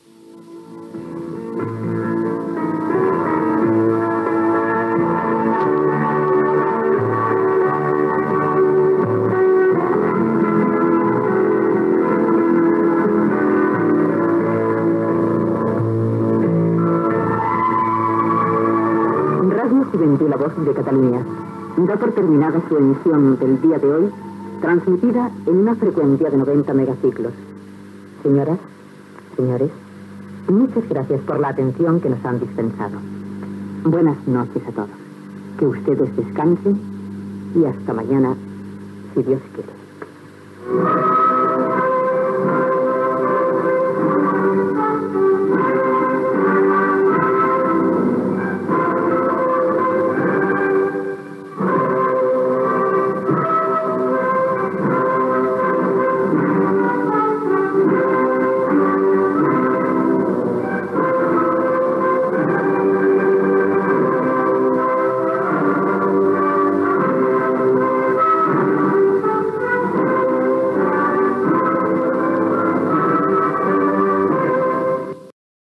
Tancament de l'emissió i Himne d'Espanya